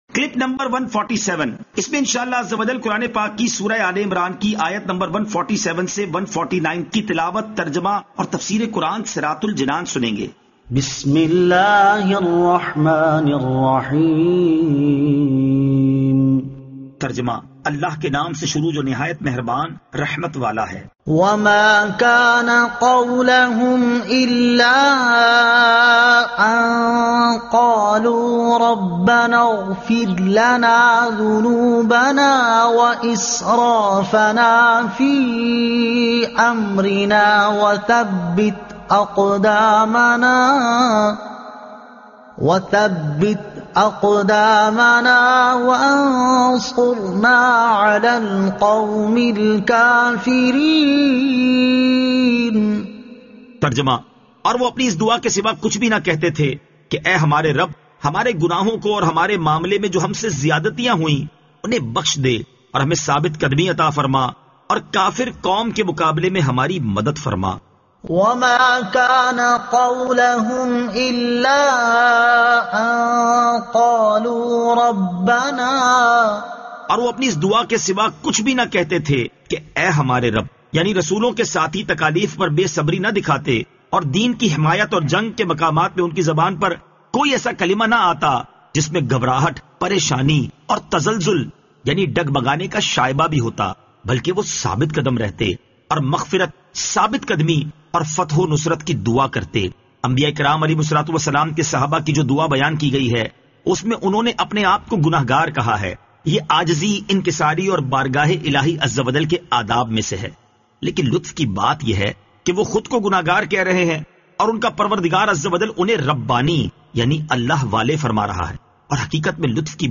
Surah Aal-e-Imran Ayat 147 To 149 Tilawat , Tarjuma , Tafseer